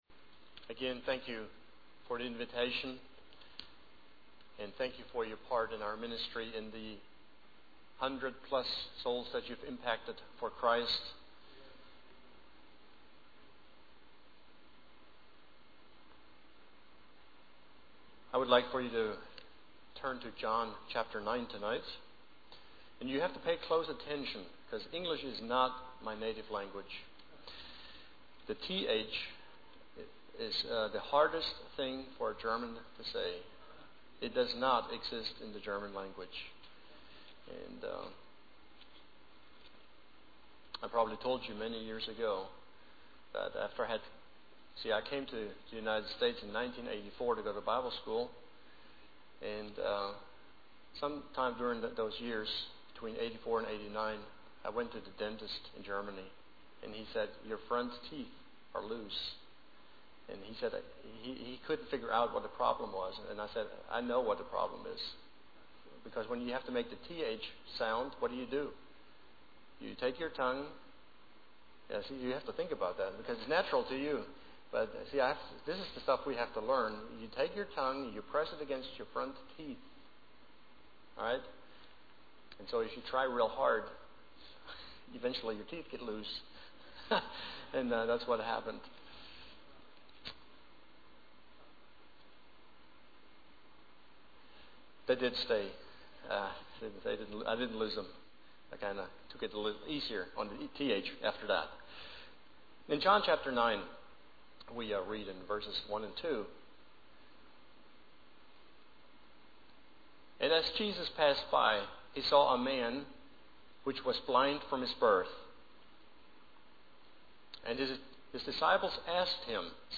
Missionary Presentation